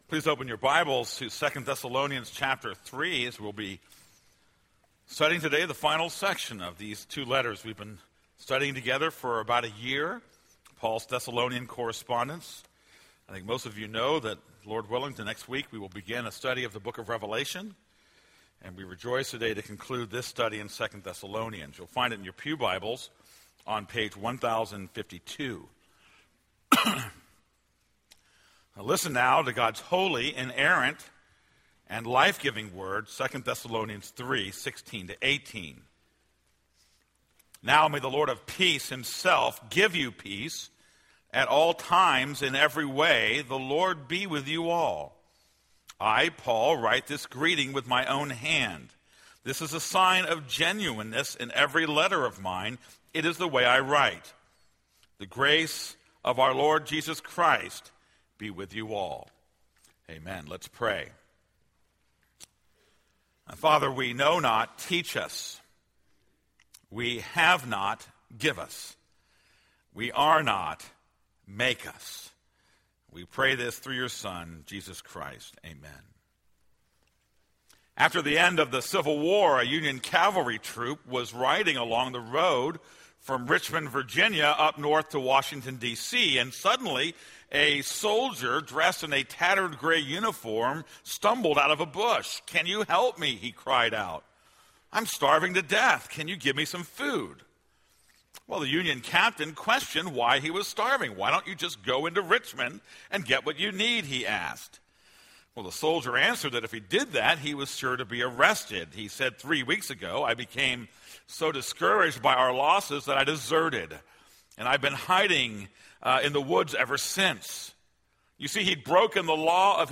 This is a sermon on 2 Thessalonians 3:16-18.